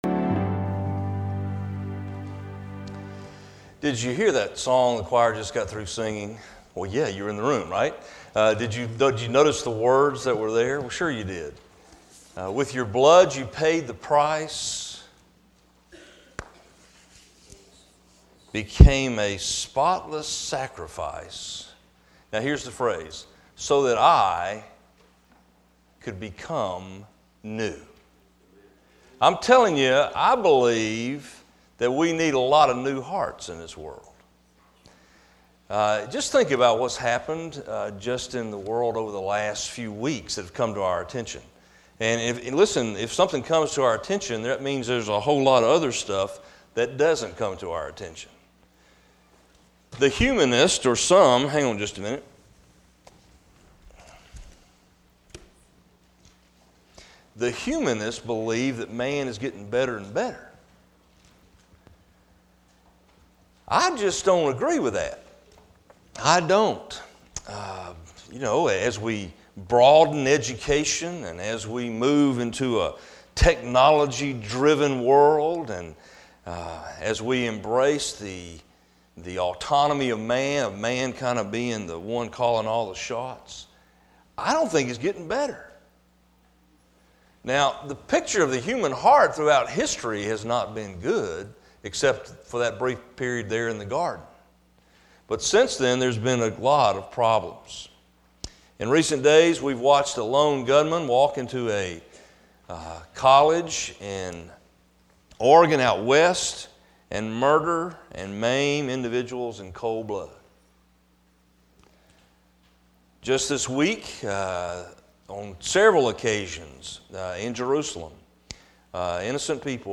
Sermons - Mt. Vernon Baptist Church